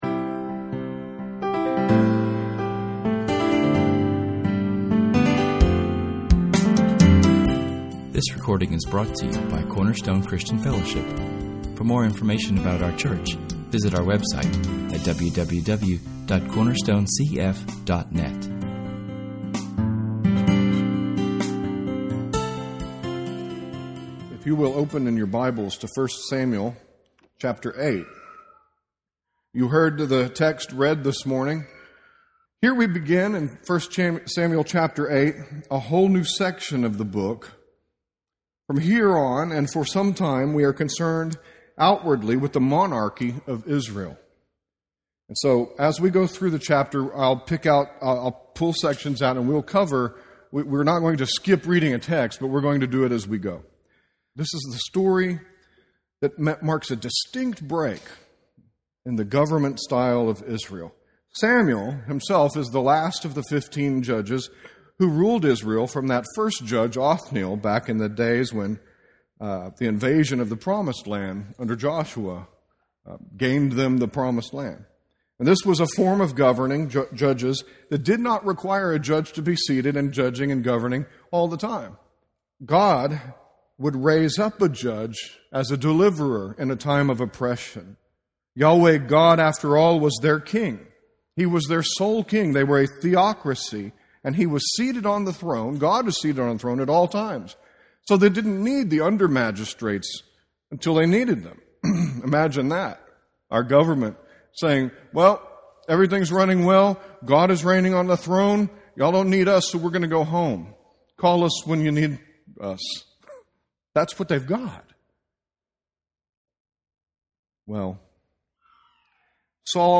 Our sermon touches on topics such as what makes a good government?
If you are listening to this sermon for the first time, please read the chapter, it is not on the audio, it was the day’s public scripture reading which is not recorded. Israel is changing, a generation has become comfortable with peace and ease.